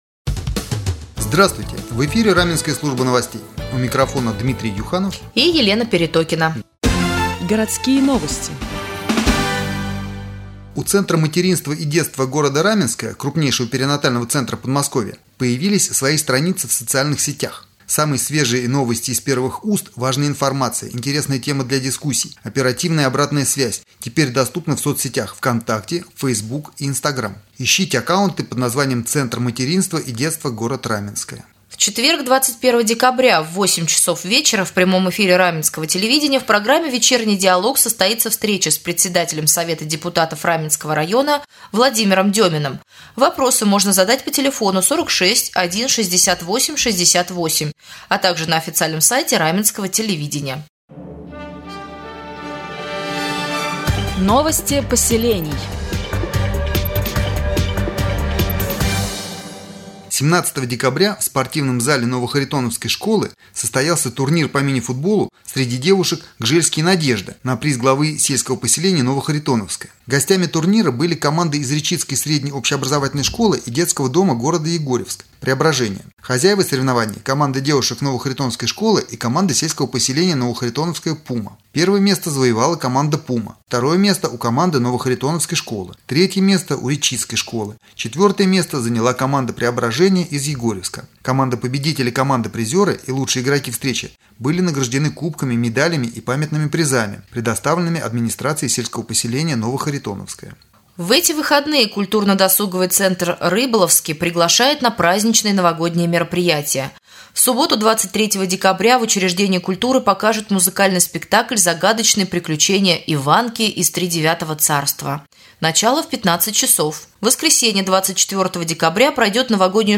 1.Новостной блок